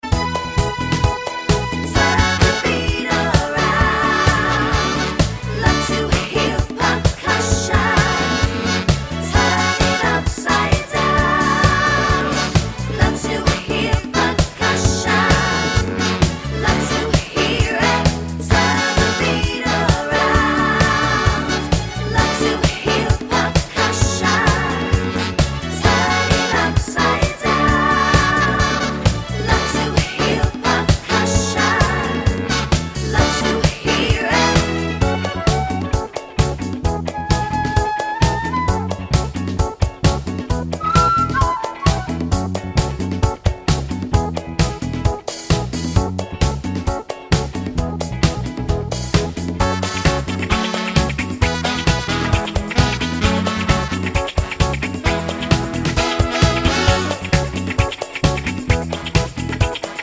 no Backing Vocals Disco 3:25 Buy £1.50